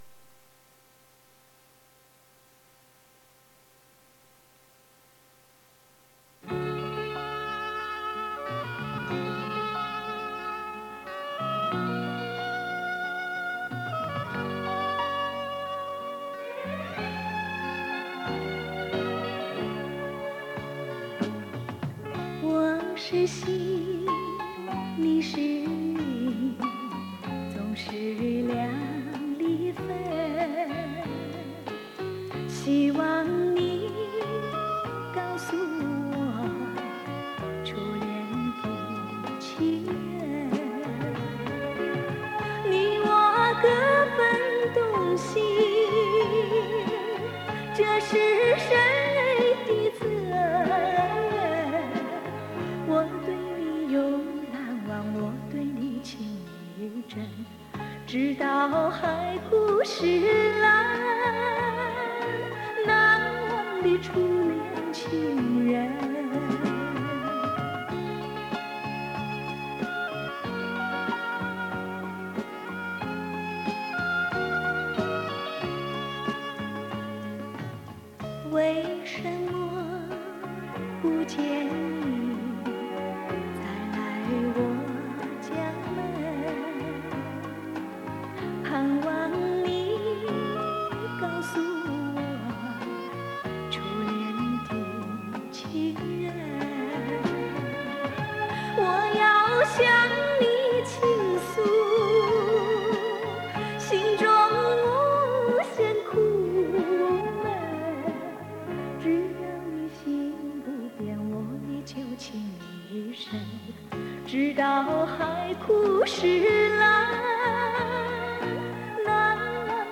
磁带数字化：2022-07-24